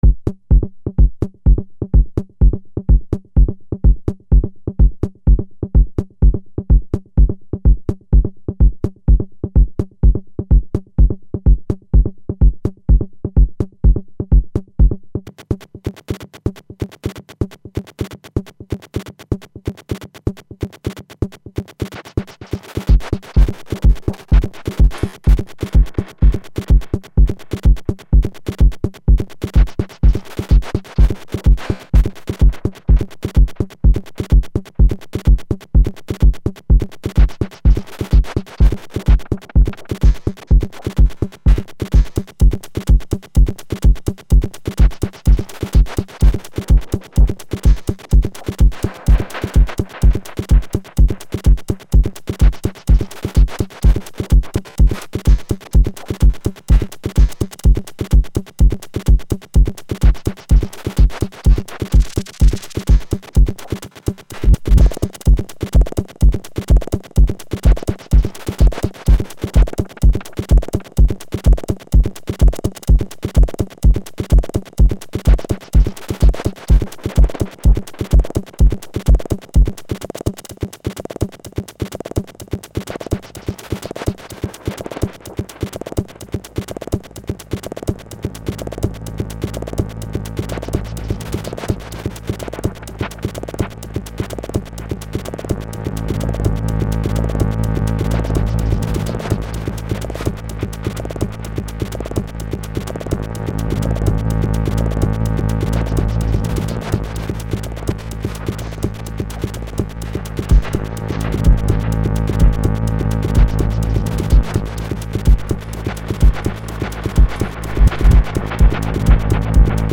analog, dark, techno, acid, deep, germany, sawtooth, 4/4